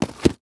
place box.wav